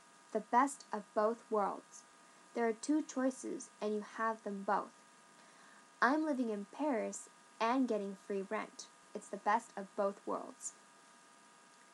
英語ネイティブによる発音は下記をクリックしてください。